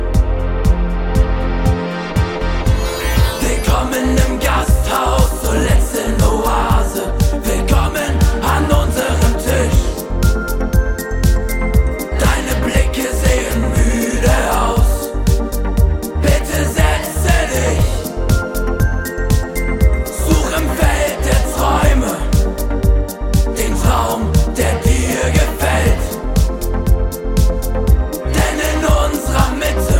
Genre: Pop > German